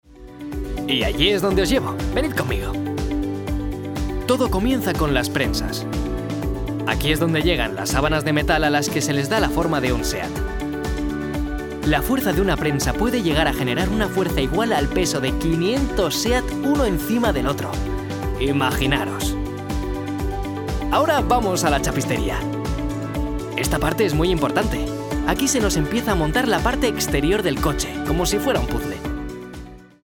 Young professional Spanish voice talent specialised in voice acting and film dubbing. Extremely versatile voice.
kastilisch
Sprechprobe: Industrie (Muttersprache):